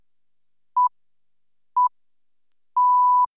countdown.wav